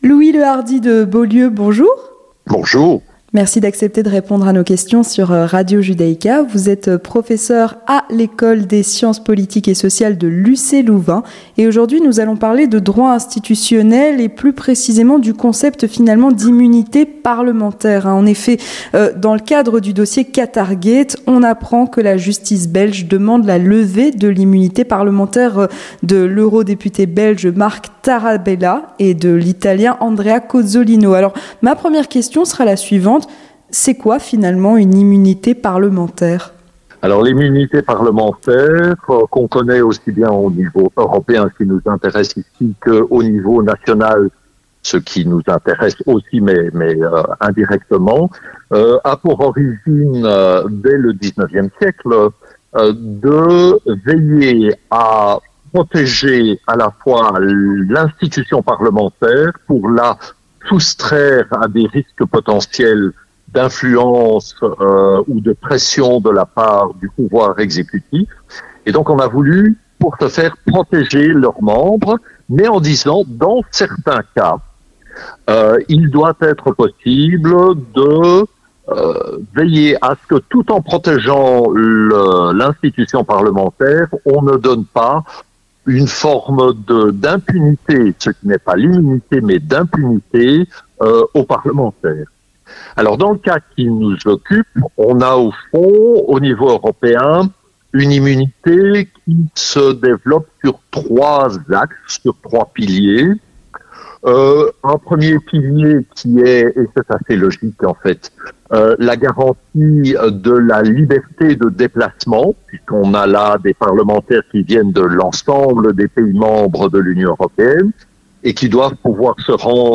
L'Entretien du Grand Journal